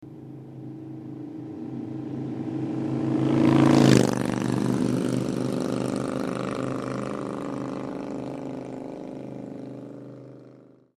Voorbij rijdend